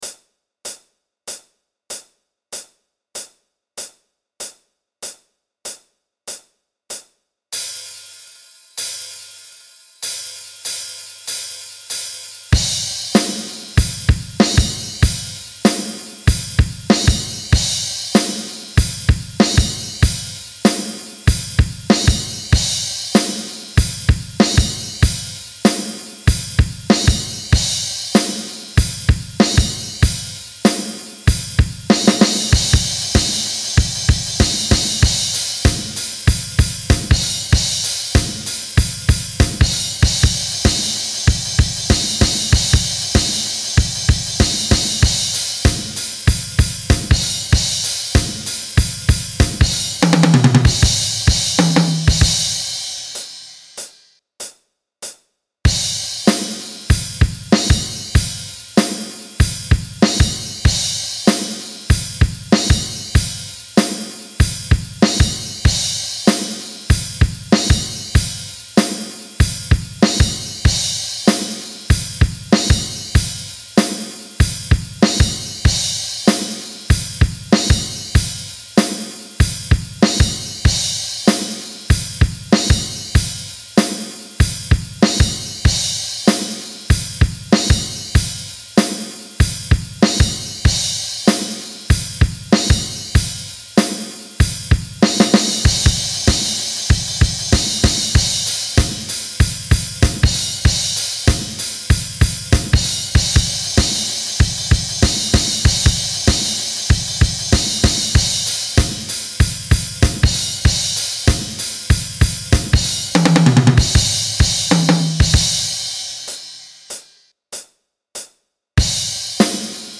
Drums only - create what you want